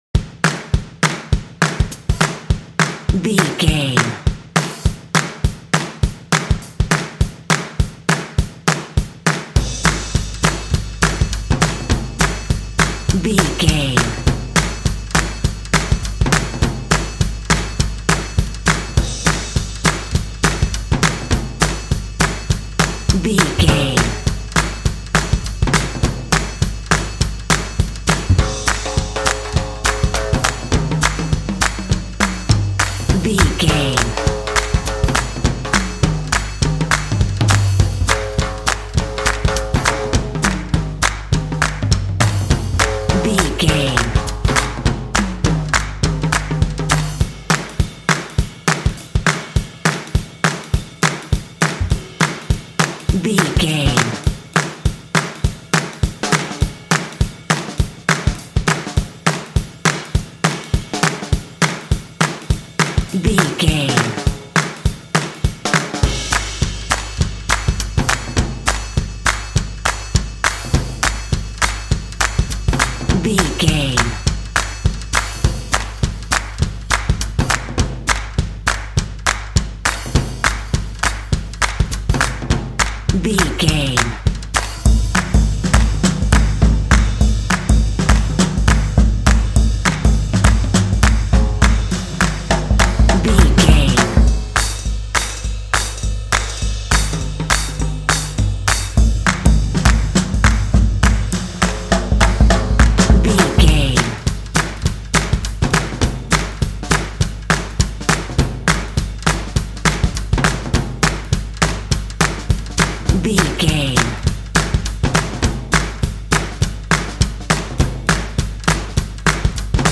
Uplifting
Aeolian/Minor
energetic
bouncy
cheerful/happy
percussion
big band